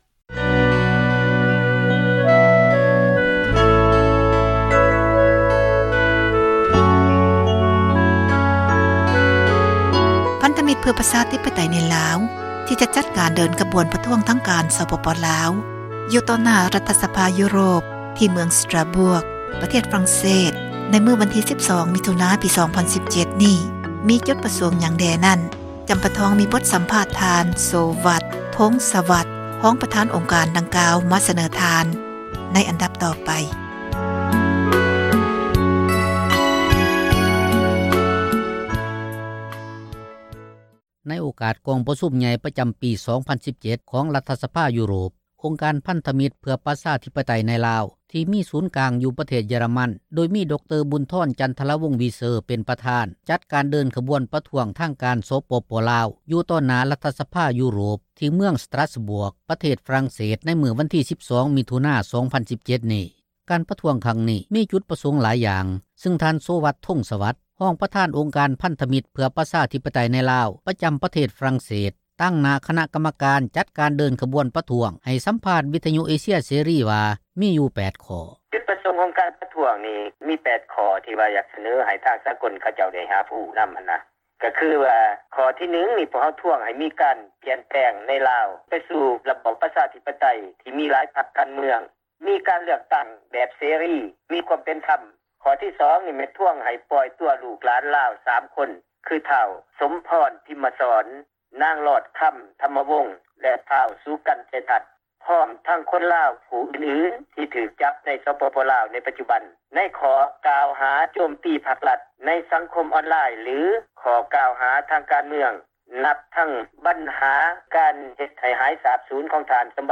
ໃຫ້ສຳພາດ ວິທຍຸເອເຊັຍເສຣີ